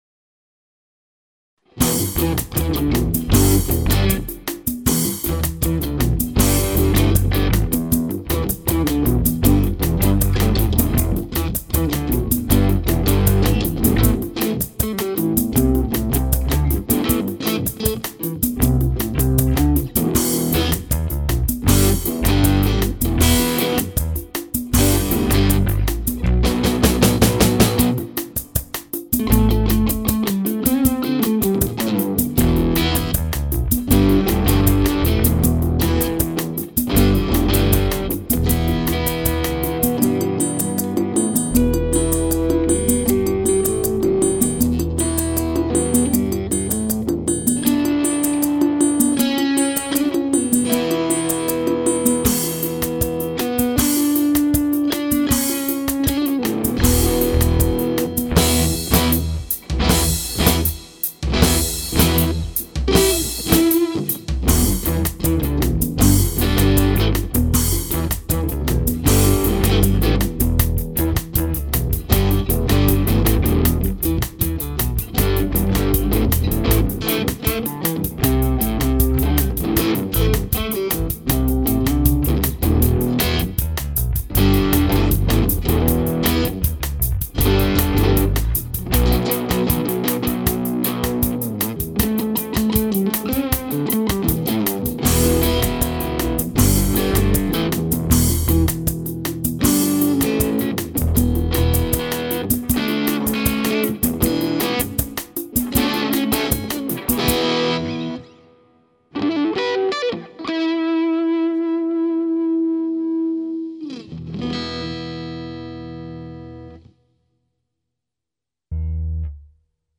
a quick instrumental put together using the Korg Pandora PX5 and its distributed control software
For this number, your host picked a few Latin beats sequences, accented by a few power rock drum and guitar stabs for the tune's bridge.
The piece is done on four tracks inside Ableton Live 6 -- Korg Edition, a crippled version of the sequencing and recording software provided with the Pandora.
Guitars were a Gretsch Electromatic bass and Epiphone Les Paul Ultra II.
And it works rather well, furnishing a glistening sound alongside the guitar's traditional Les Paul meat 'n' potatoes rock tone.
At the end of the tune, there's a segue from the Les Paul's saturated arena tone to an acoustically strummed final chord, done by merely twisting one of the instrument's volume knobs.